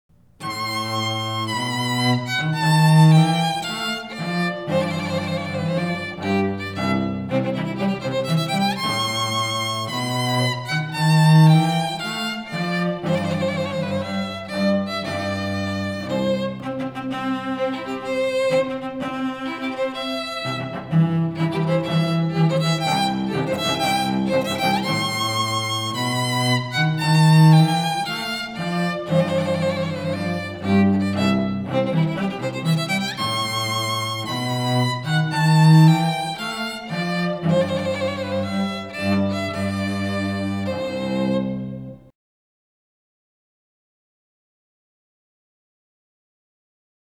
VIOLIN/CELLO DUET SAMPLES